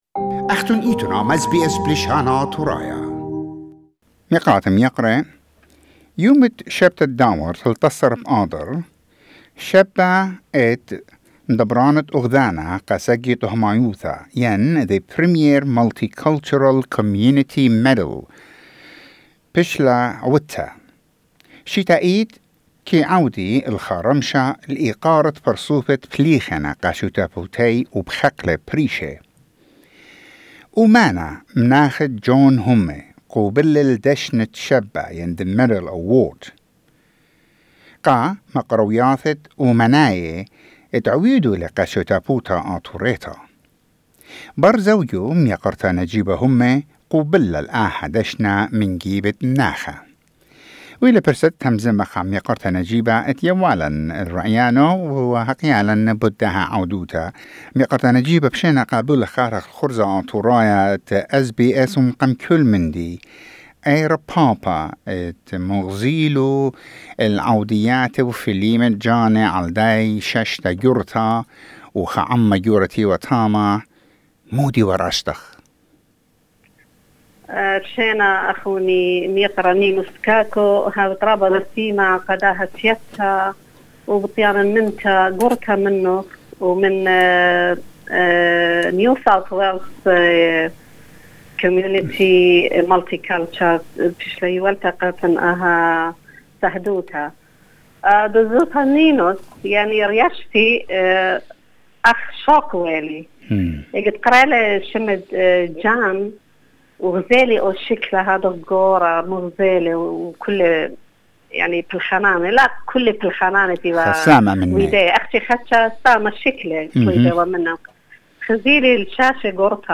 SBS Assyrian